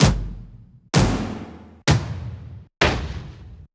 yell-hit-64bpm-1beat.ogg